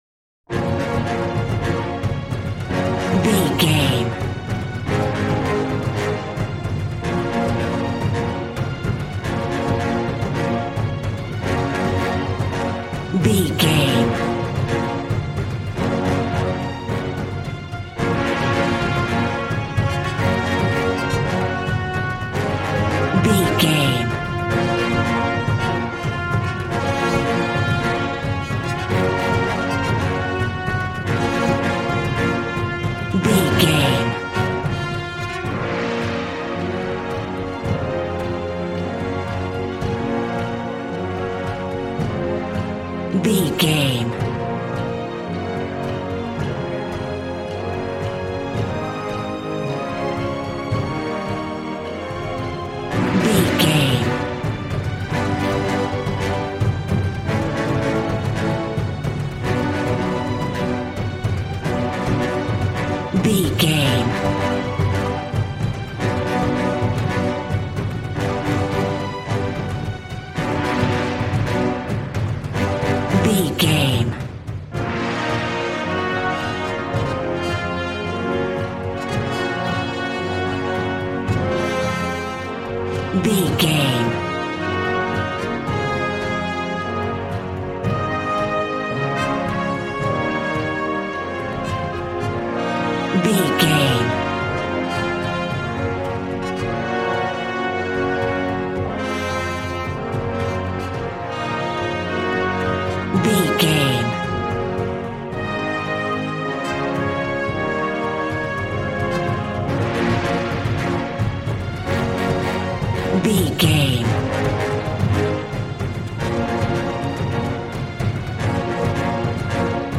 Aeolian/Minor
B♭
regal
cello
double bass